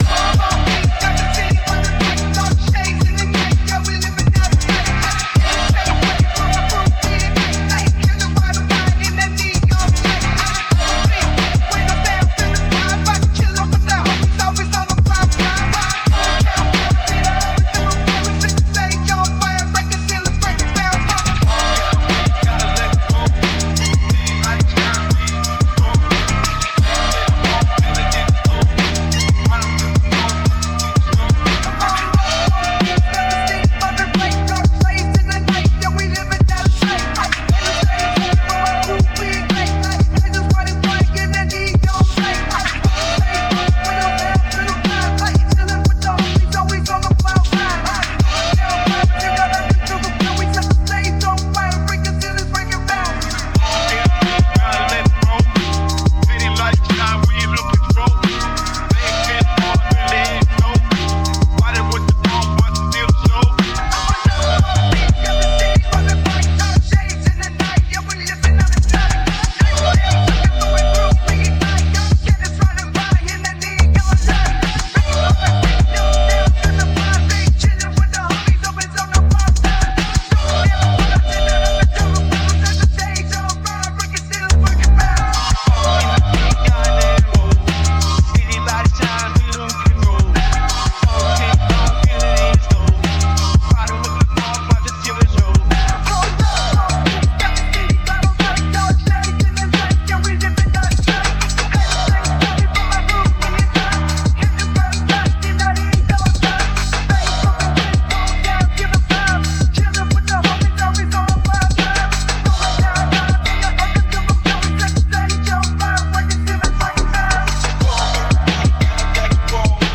male vocalist, hip hop, hardcore hip hop, rhythmic, boastful, urban, rap, sampling, phonk